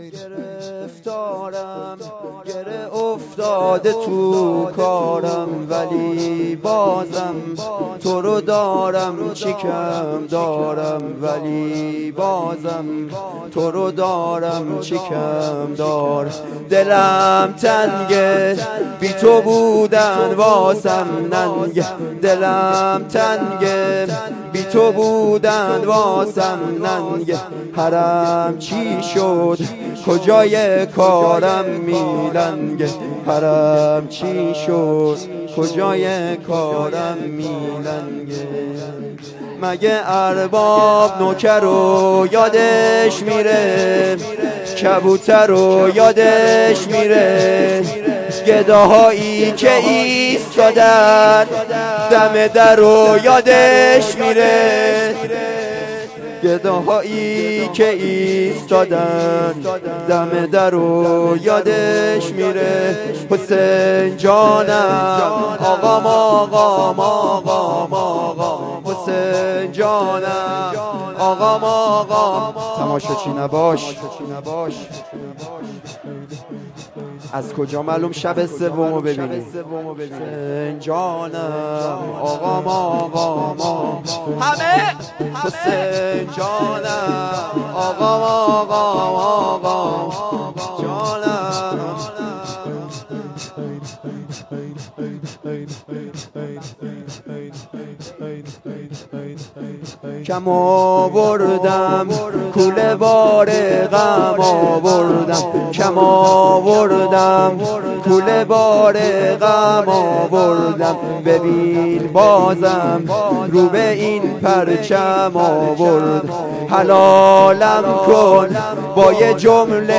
شور
شب دوم ماه محرم